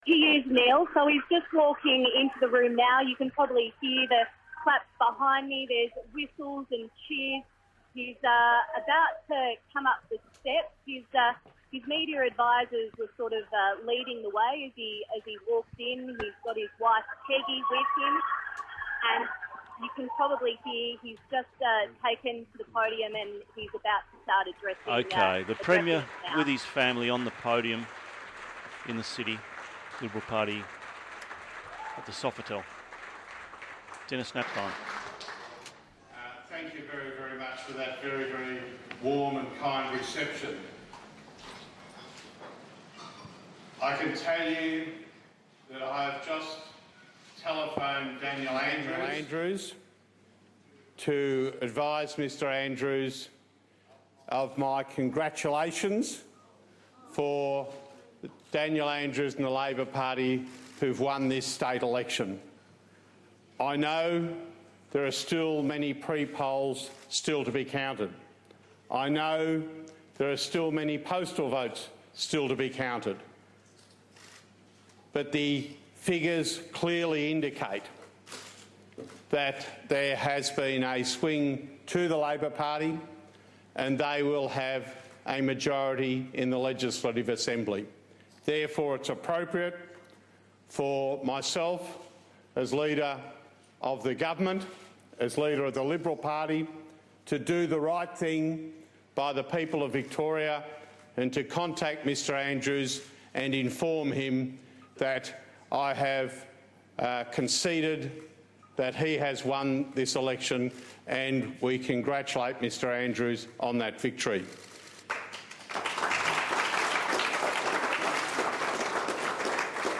Denis Napthine concedes the 2014 Victorian election.